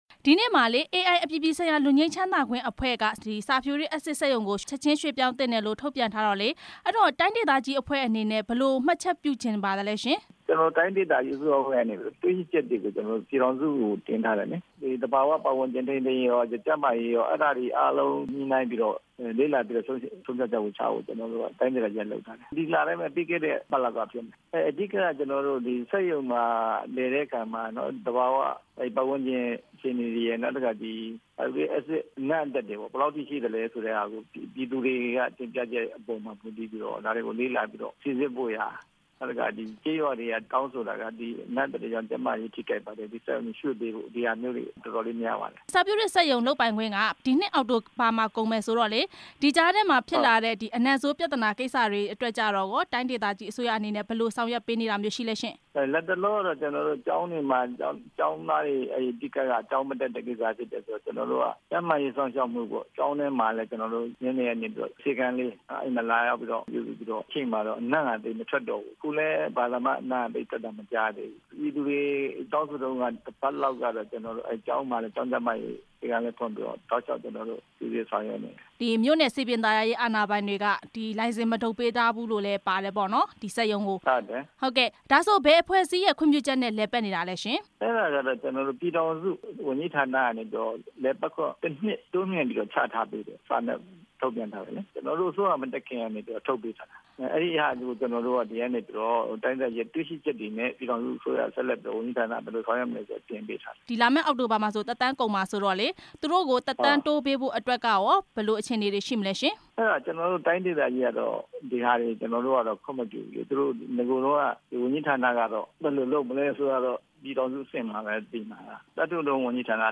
အက်ဆစ်စက်ရုံကိစ္စ မေးမြန်းချက်